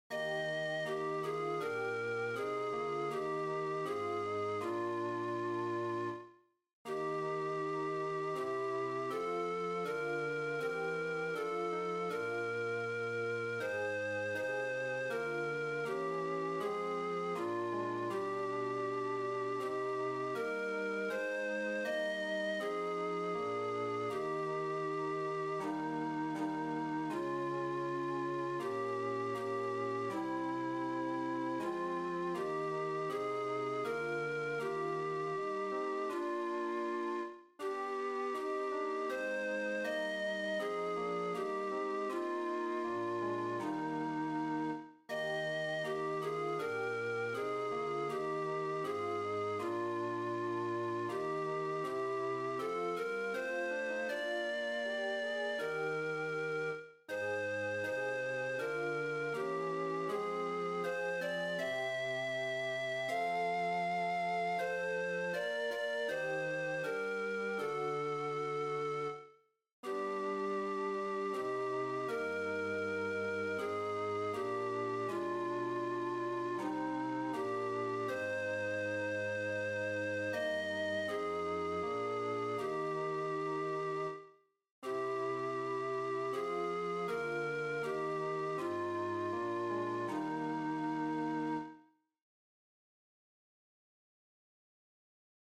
Chorproben MIDI-Files 497 midi files